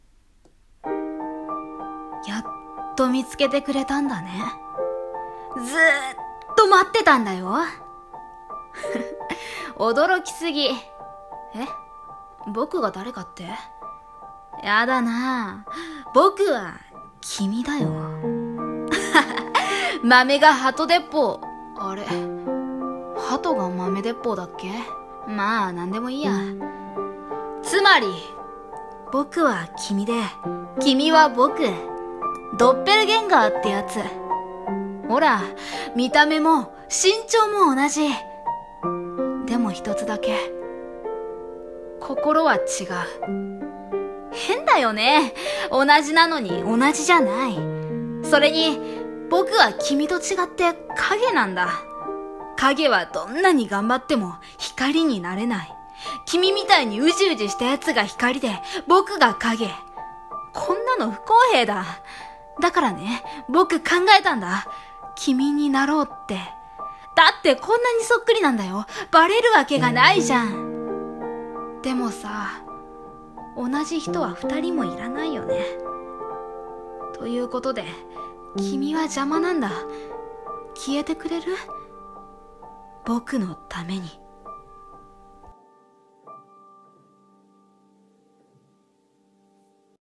声劇】ドッペルゲンガー